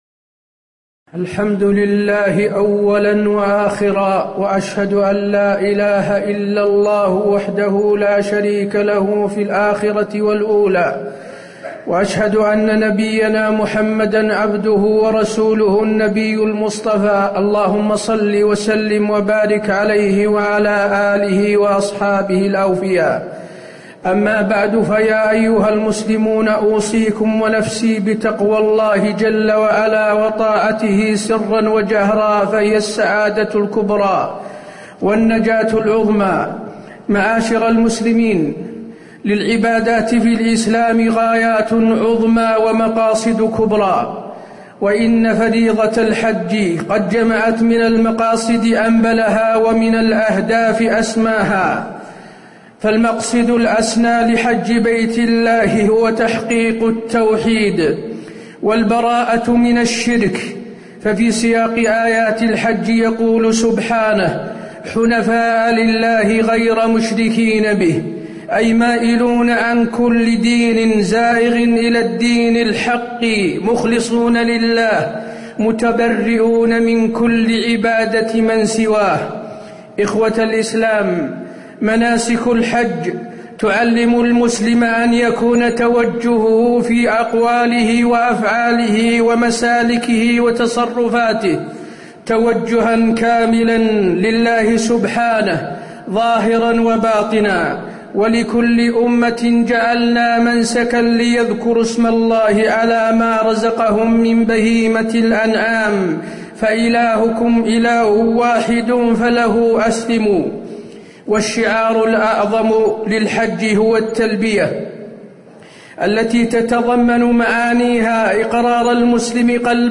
تاريخ النشر ١٦ ذو القعدة ١٤٣٧ هـ المكان: المسجد النبوي الشيخ: فضيلة الشيخ د. حسين بن عبدالعزيز آل الشيخ فضيلة الشيخ د. حسين بن عبدالعزيز آل الشيخ تعظيم شعائر الله وحرماته في الحج